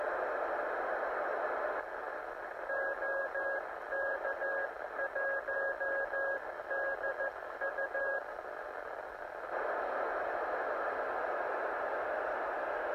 TRX Yaesu FT-991
Ant. bílá hůl ve výšce cca 5 m